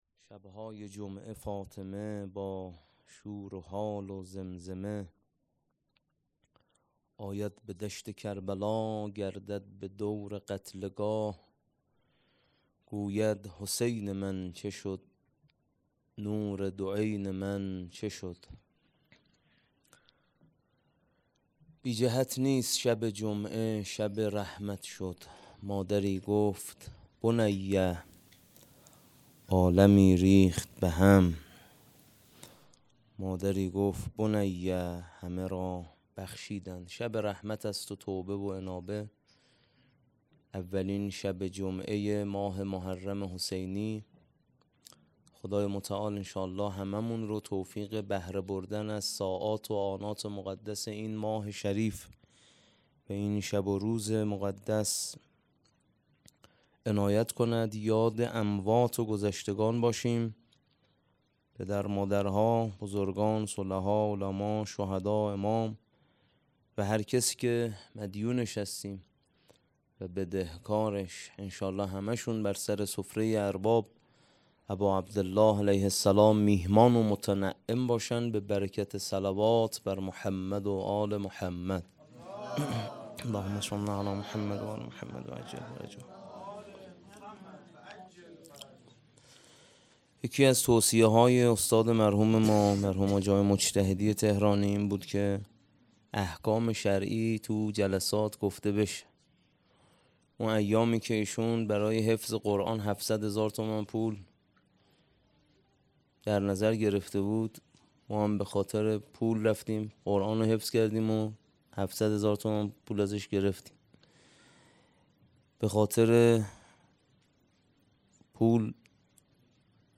هيأت یاس علقمه سلام الله علیها